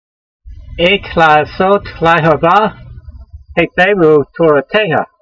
v126_voice.mp3